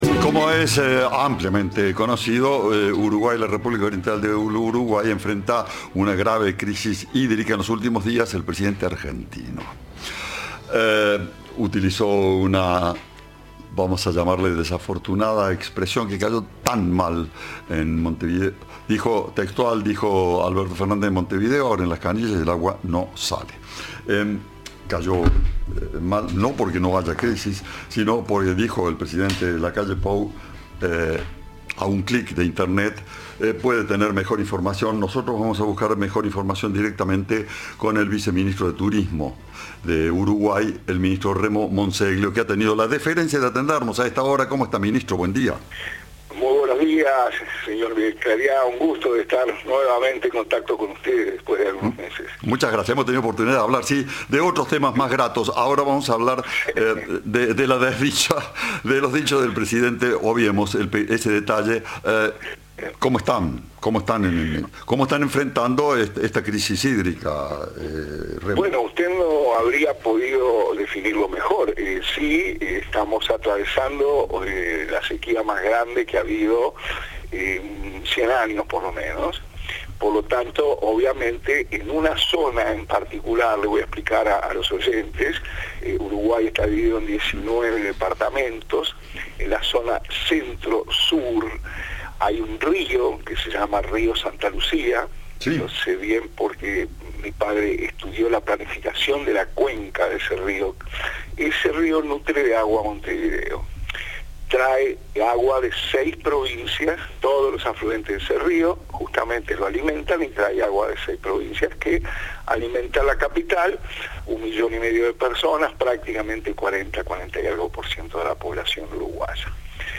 El viceministro de Turismo de Uruguay, Remo Monzeglio, habló con Cadena 3 sobre la crisis hídrica en Montevideo y aseguró que hay información errónea circulando en Argentina.
Entrevista